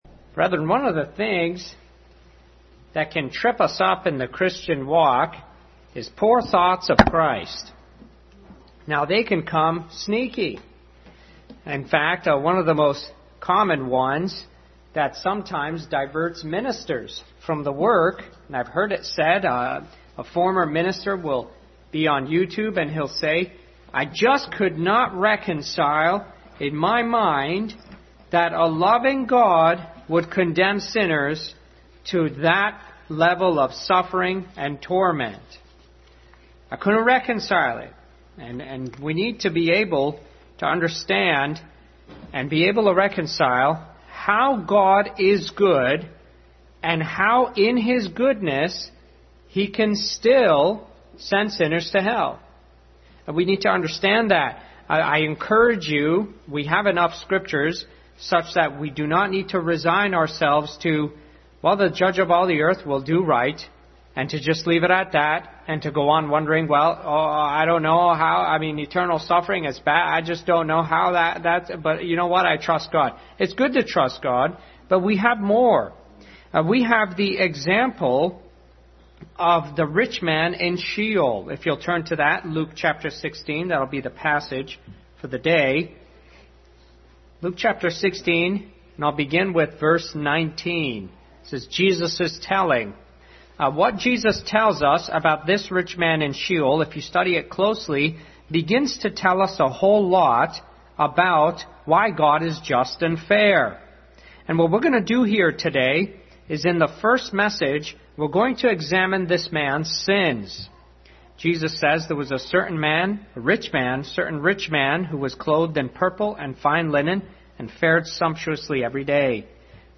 Adult Sunday School.